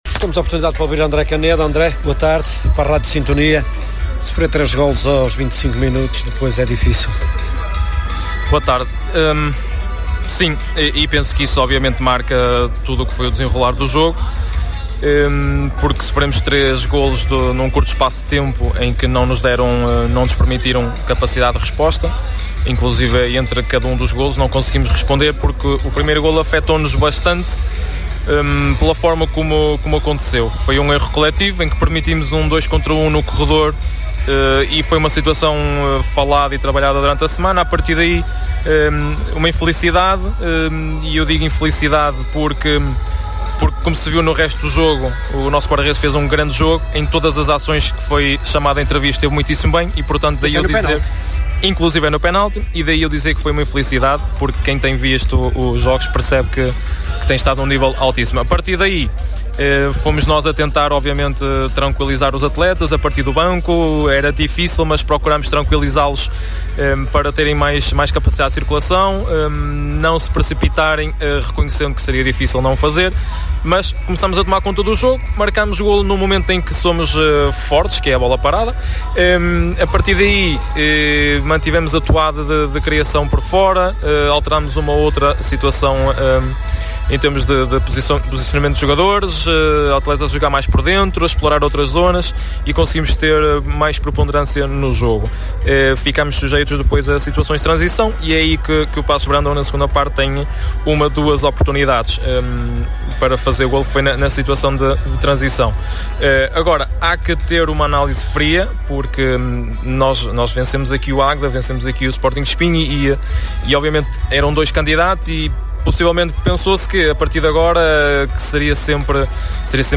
acompanhou as emoções no campo das Valadas e ouviu os protagonistas no final.